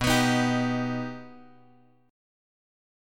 B+ chord {x 2 x 4 4 3} chord
B-Augmented-B-x,2,x,4,4,3.m4a